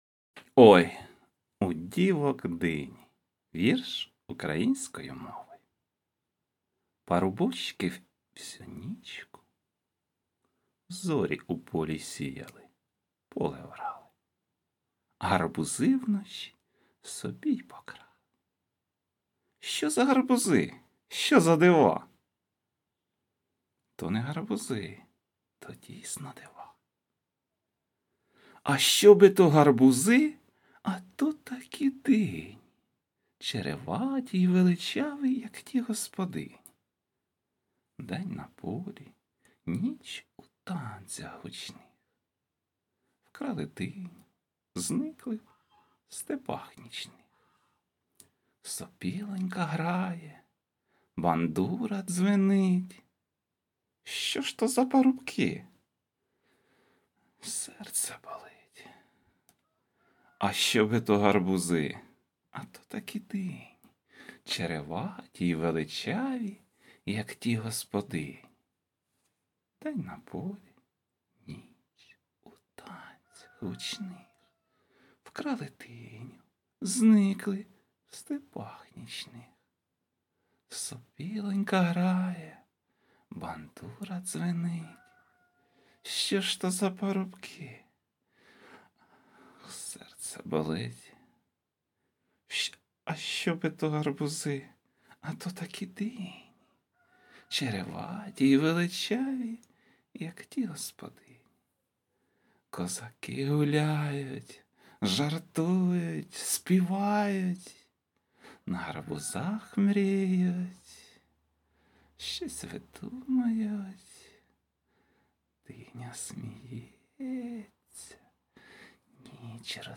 ТИП: Поезія
У мене і є ця пісня, та хіба я не гарно продекламував, тут же клуб поезії наче biggrin